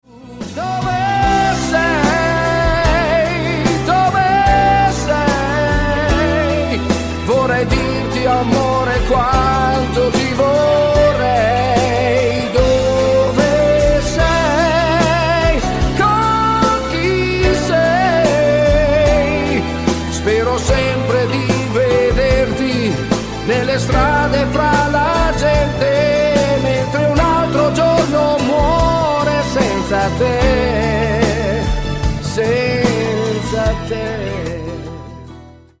SLOW  (4.12)